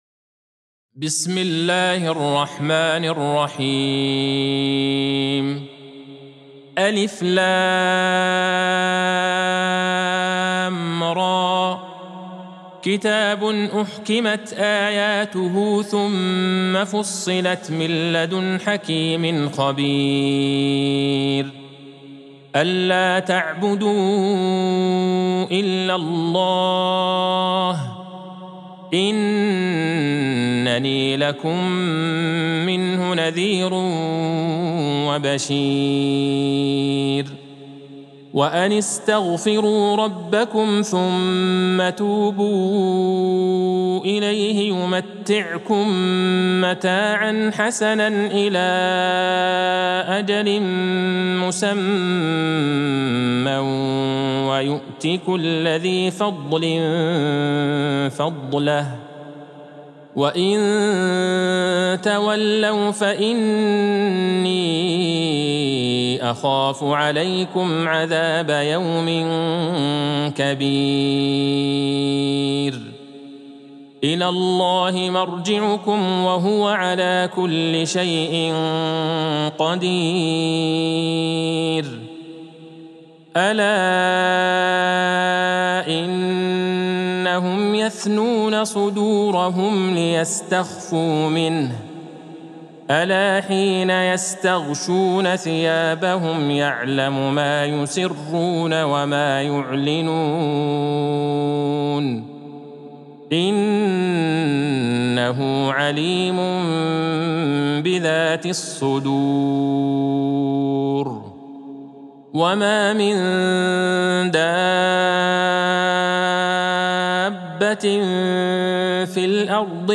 سورة هود Surat Hud | مصحف المقارئ القرآنية > الختمة المرتلة ( مصحف المقارئ القرآنية) للشيخ عبدالله البعيجان > المصحف - تلاوات الحرمين